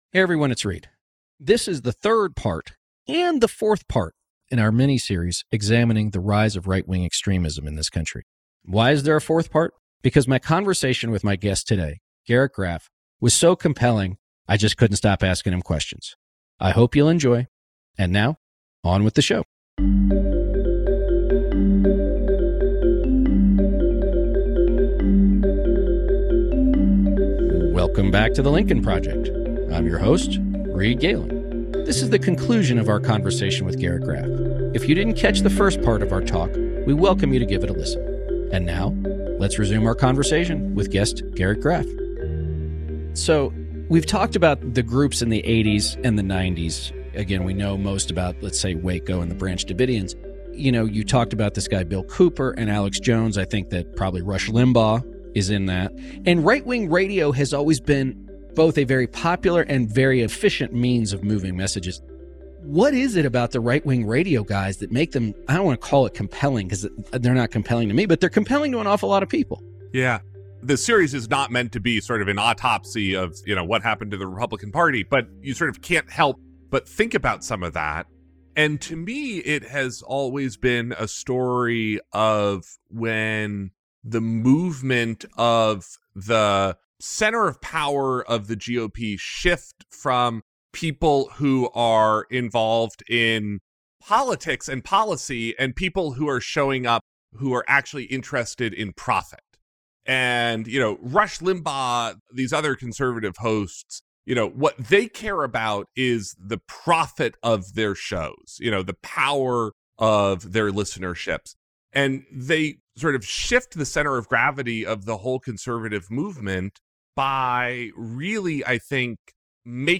In part two of a two-part conversation, they discuss how the American far-right extremist movement went from being on the fringe to being accepted by the mainstream, how political violence and its associative rhetoric has become frighteningly more common (and acceptable), and why this moment in American politics is so pivotal.